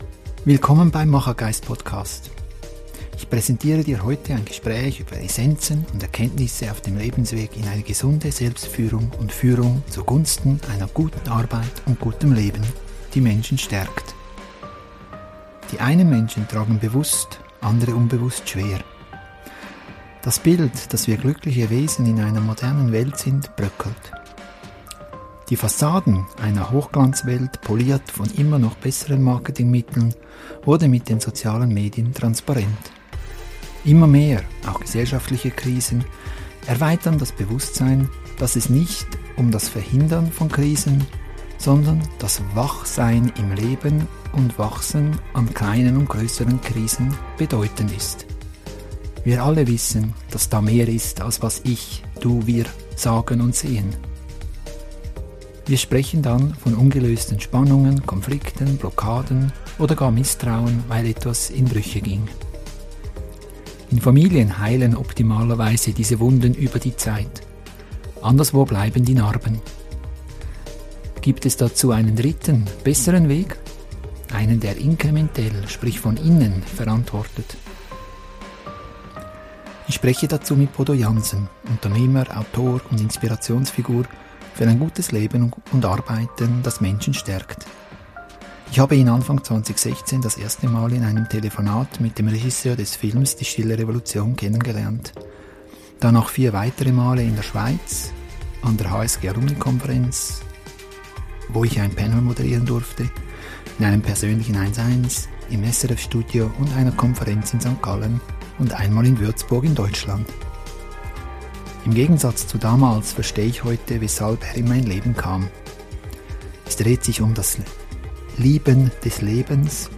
ein Gespräch über Wachsamkeit, Schatten, Führen & Verantworten ~ Machergeist Podcast